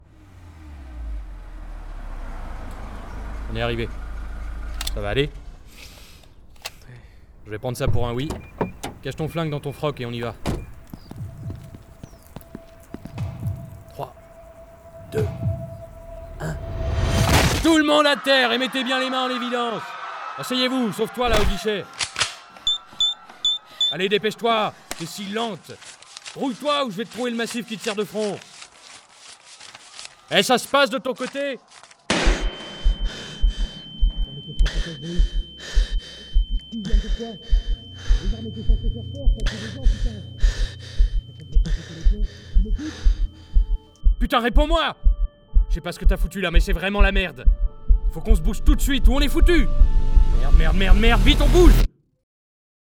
Voix off
Bandes-son
Publicité radio